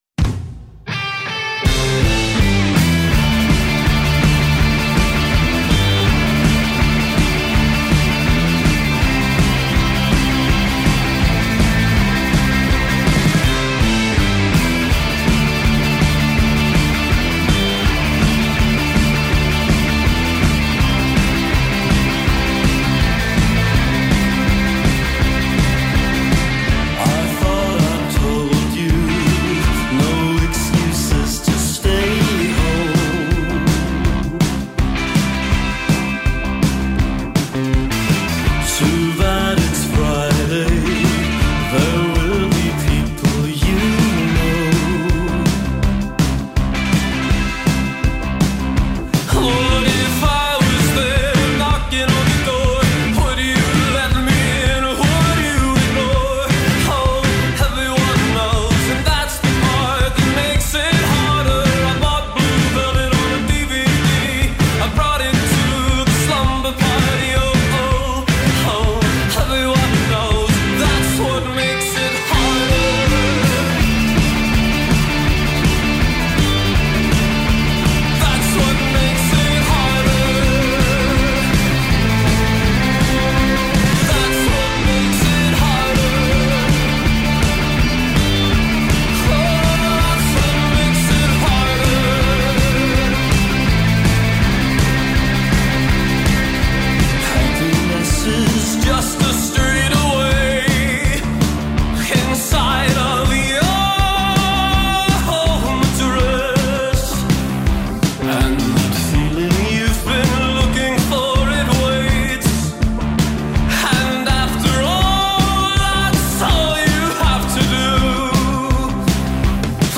Ascolta l’intervista ai Brigitte Calls Me Baby.